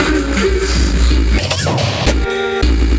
80BPM RAD8-R.wav